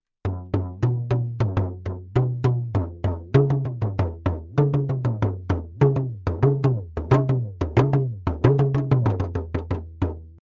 Les membranophones
tama.mp3